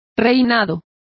Also find out how reinados is pronounced correctly.